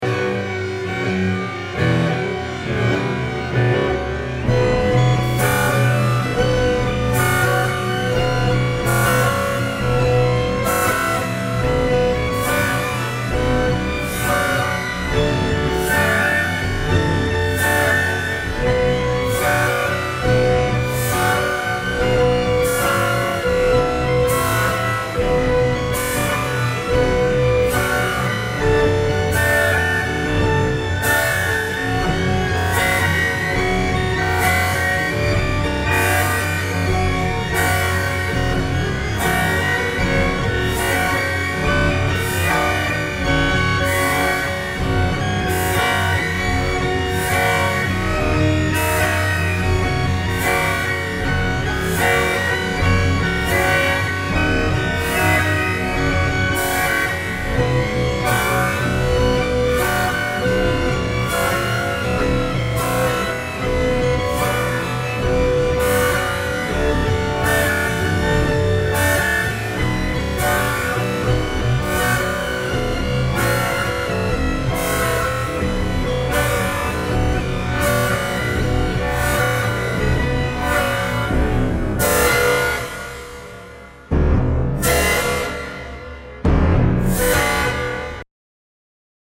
Here is the music slowed down: